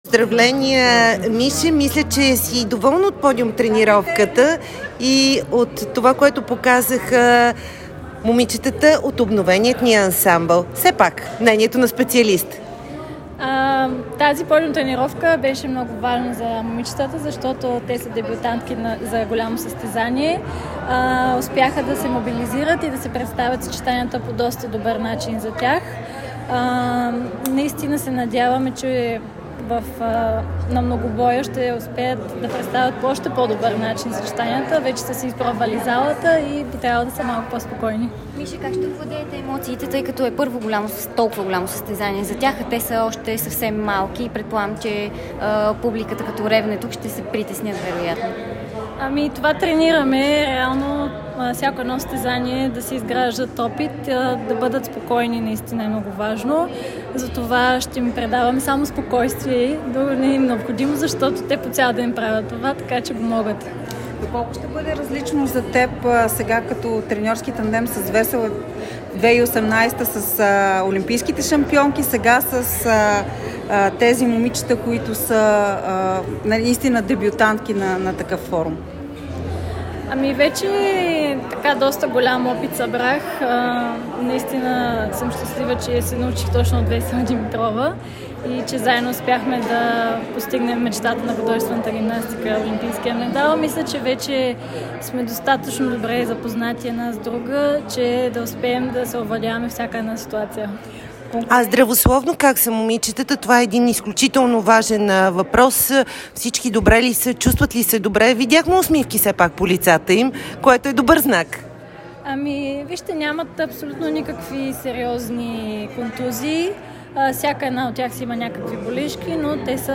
Помощник треньорът на ансамбъла по художествена гимнастика Михаела Маевска говори след подиум тренировката и преди старта на Световното първенство в София.